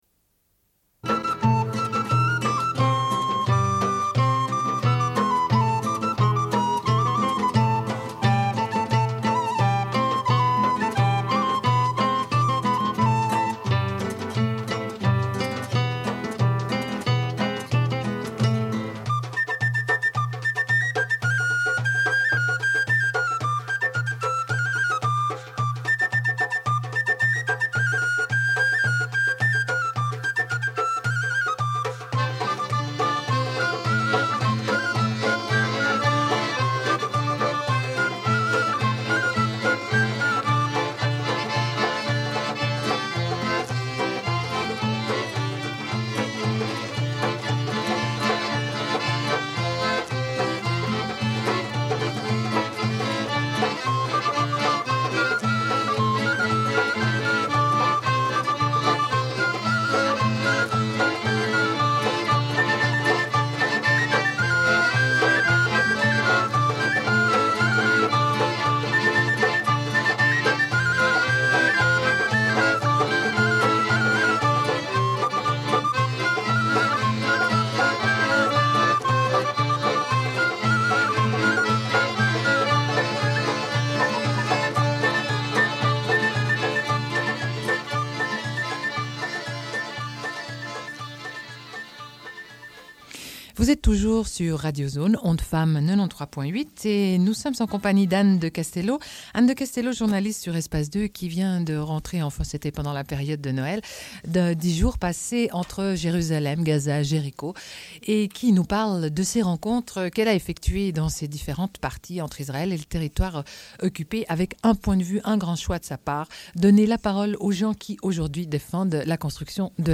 Une cassette audio, face B29:08